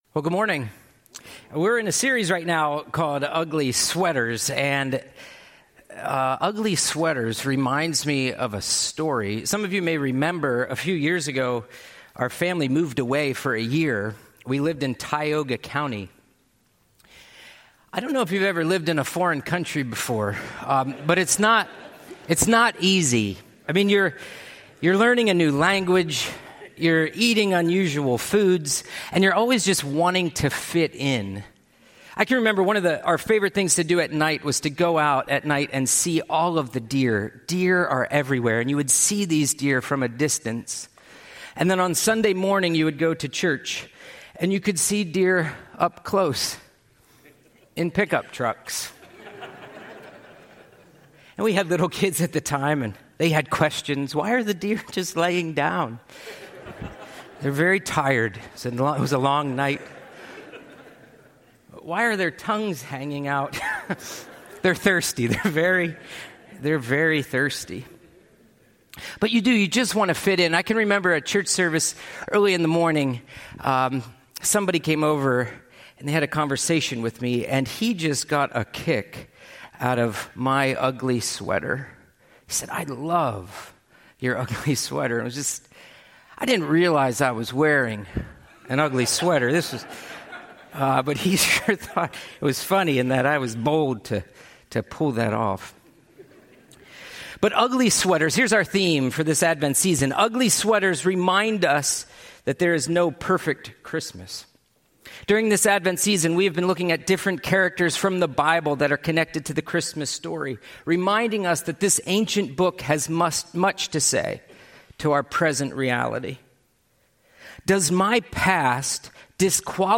by Guest speaker